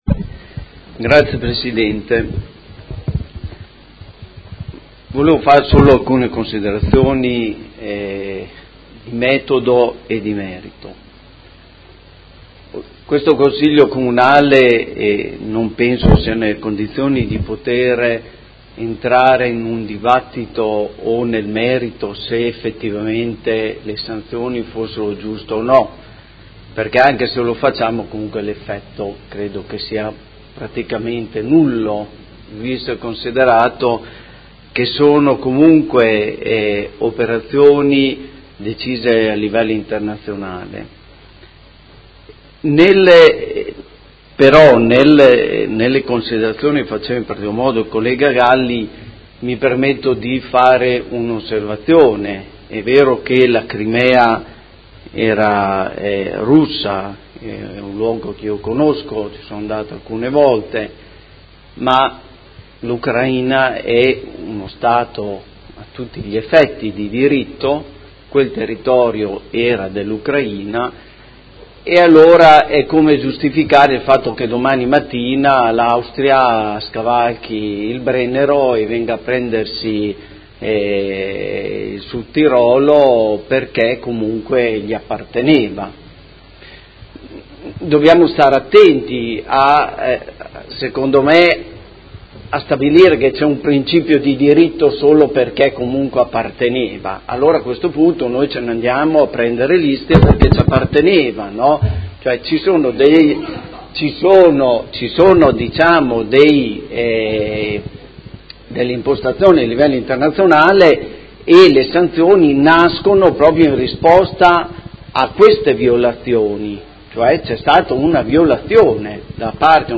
Antonio Montanini — Sito Audio Consiglio Comunale